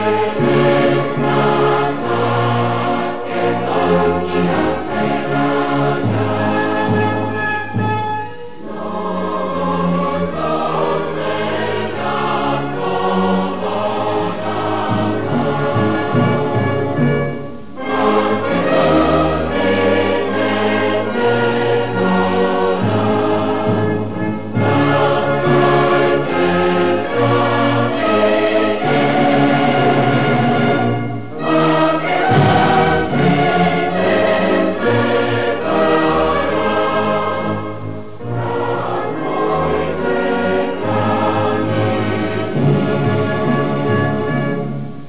Lyrics (wav file)
anthem.wav